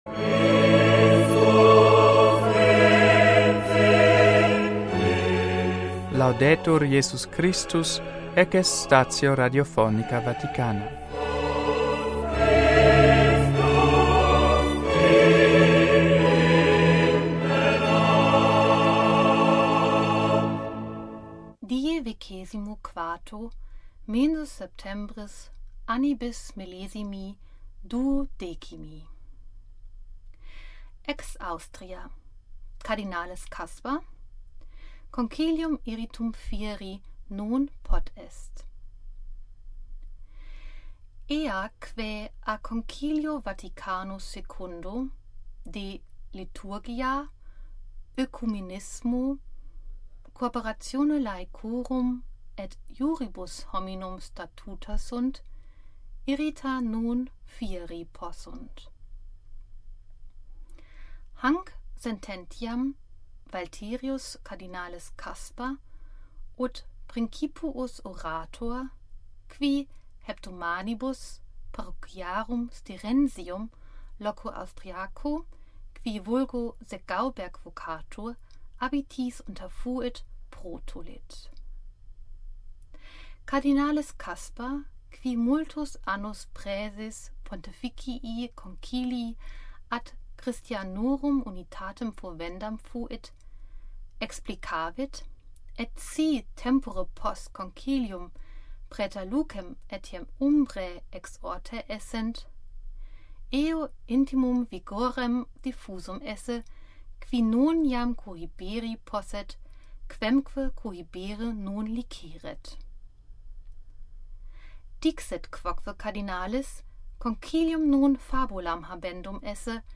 NUNTII STATIONIS RADIOPHONICAE VATICANAE PARTITIONIS GERMANICAE IN LINGUAM LATINAM VERSI